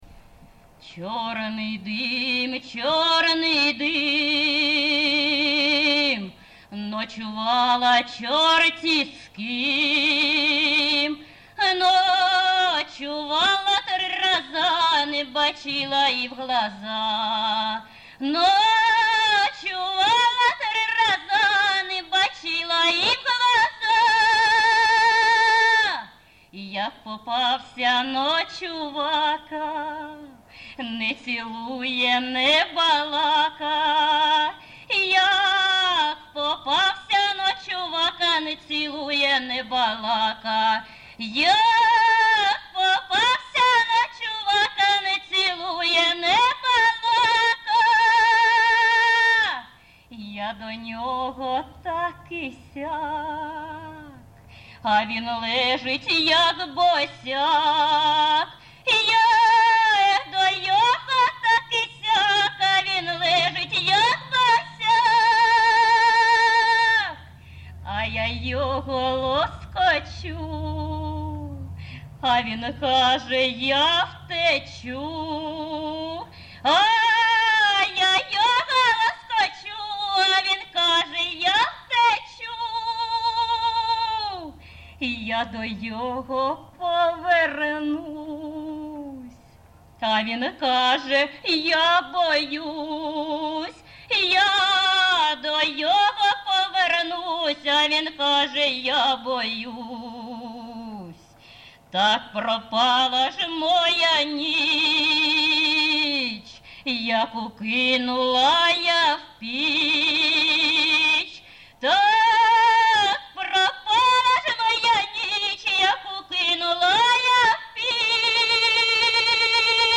Місце записус. Гусарівка, Барвінківський район, Харківська обл., Україна, Слобожанщина
Виконавиця співає не в традиційній, а в сценічній манері